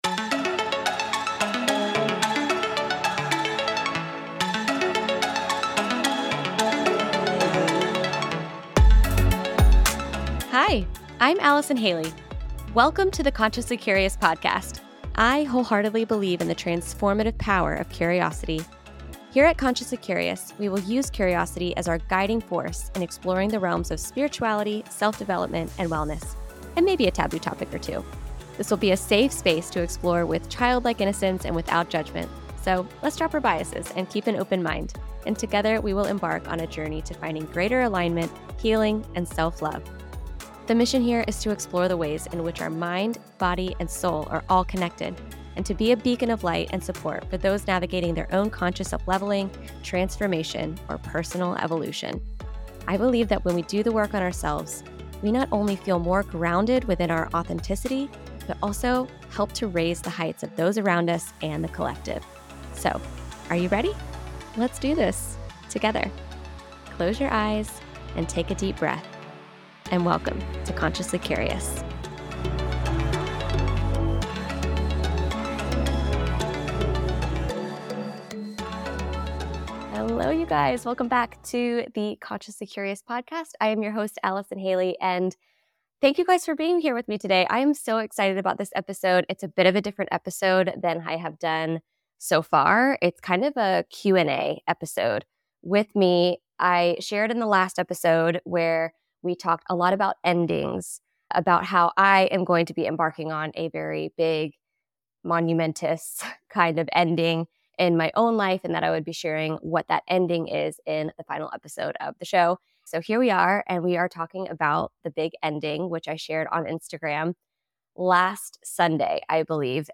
In today’s cozy Q&A episode, I’m sharing the big news I teased on Instagram: WE LEFT ENGLAND & MOVED TO THE US!